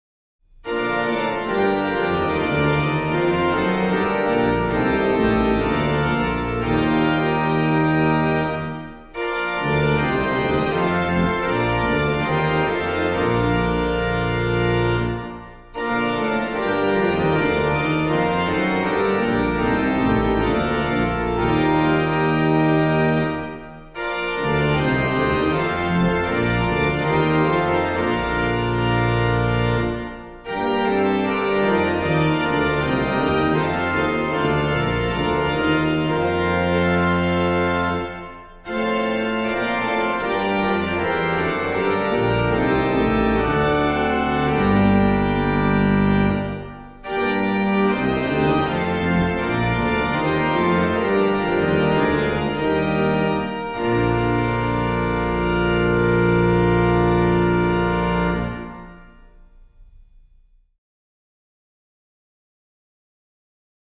Subtitle   Figured-bass chorale
Registration   MAN: Qnt16, Pr8, Bor8, Oct4, Qnt3, Oct2, Mix
PED: Viol16, Oct8, Pos16, MAN/PED